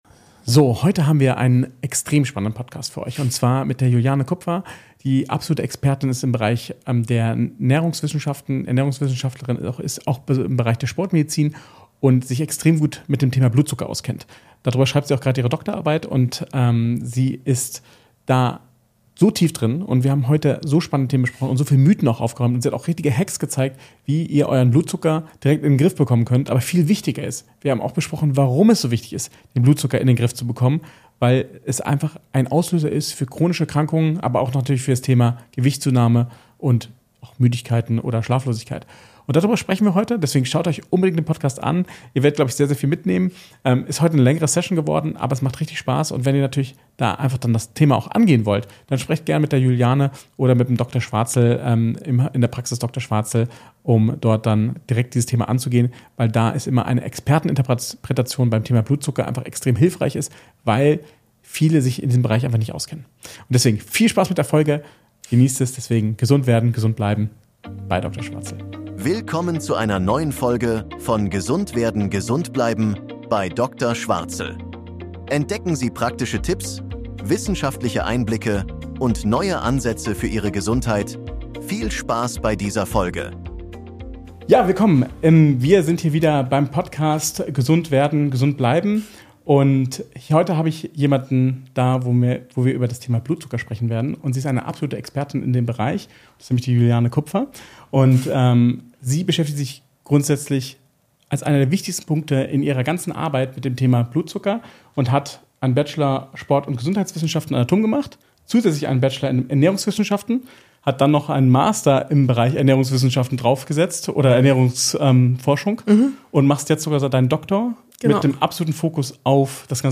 Blutzucker außer Kontrolle? Das eine Detail, das ALLES verändert | Interview